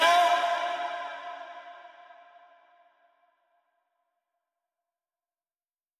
错误音效
描述：错误音效 啊哦
标签： 音效 错误 啊哦
声道单声道